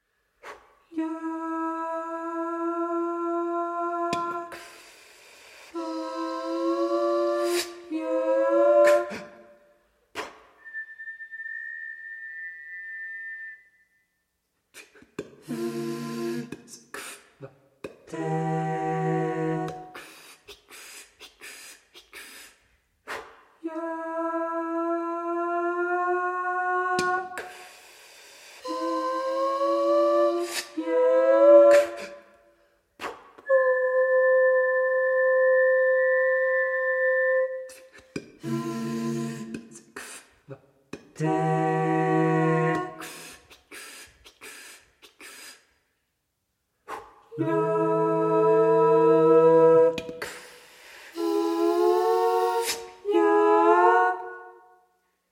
• Genres: Classical, Vocal
New York based contemporary vocal ensemble